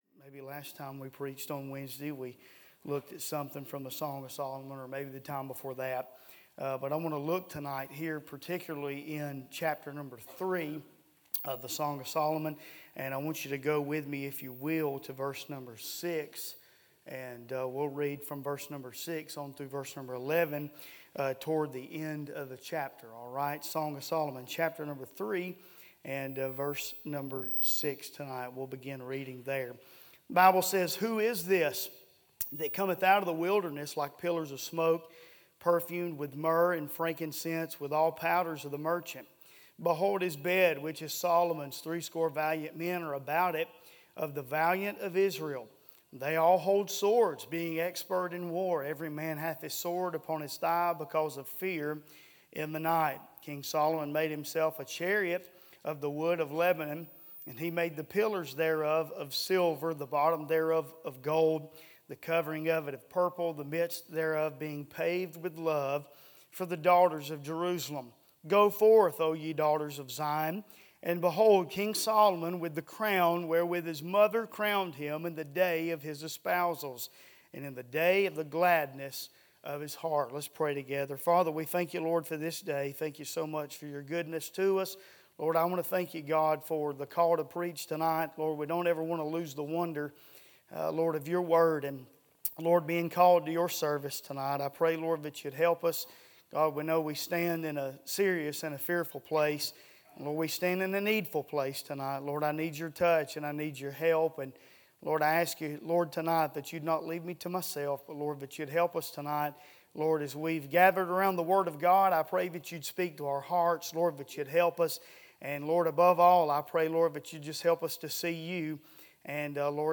A sermon preached Wednesday Evening, on May 14, 2025.